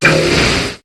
Cri de Blindalys dans Pokémon HOME.